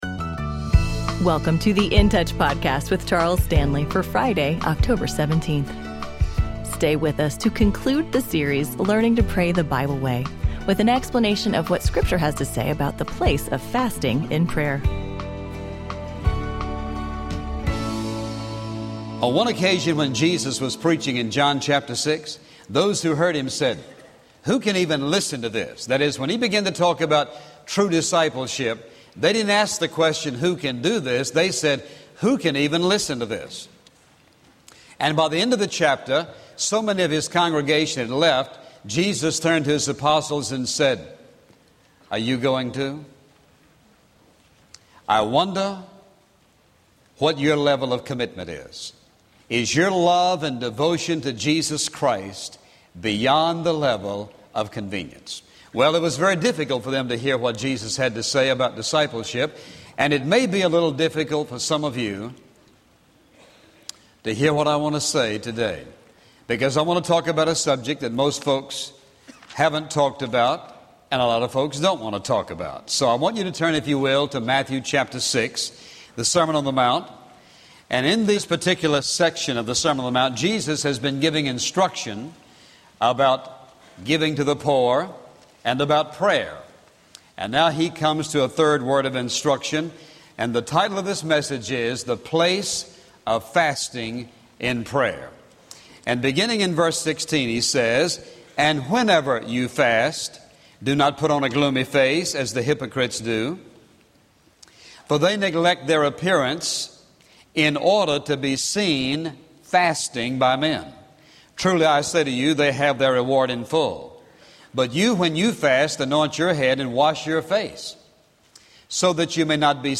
Dr. Stanley talks about finding God's will as he unveils the richness of the biblical phenomenon of fasting and prayer.